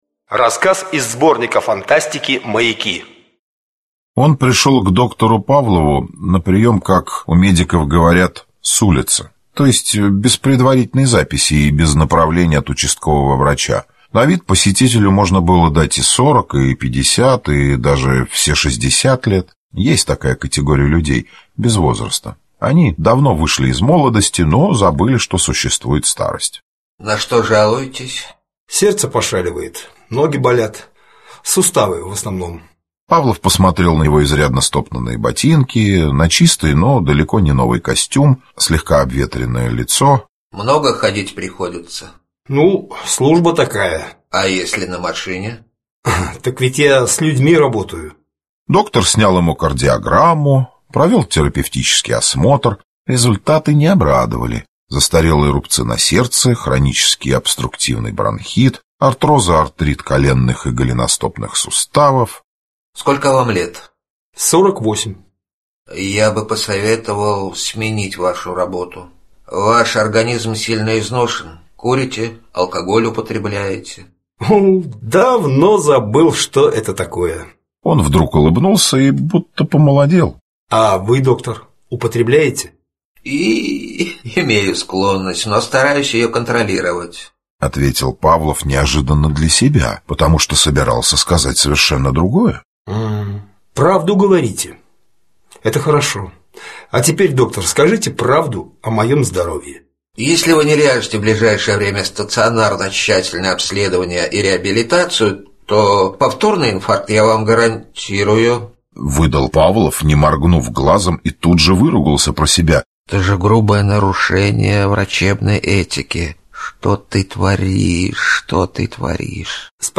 Аудиокнига День, когда исчезла ложь | Библиотека аудиокниг